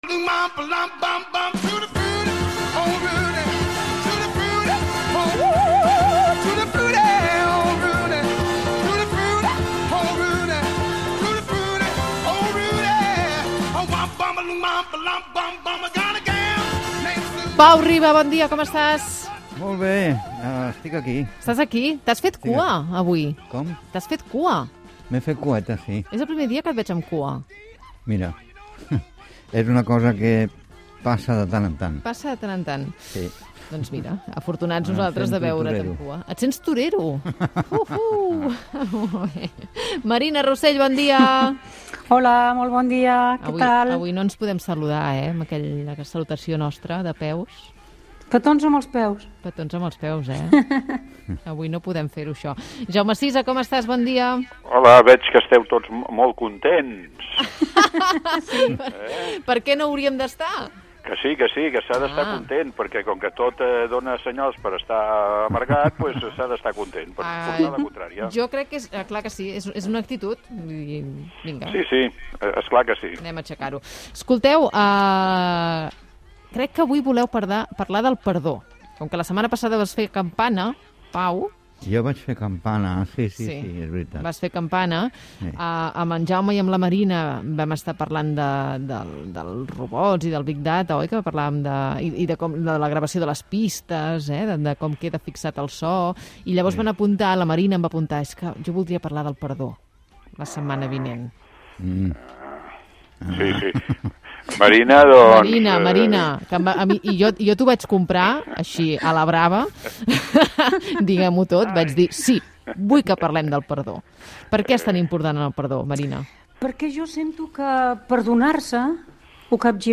presentació i tertúlia sobre el perdó
Info-entreteniment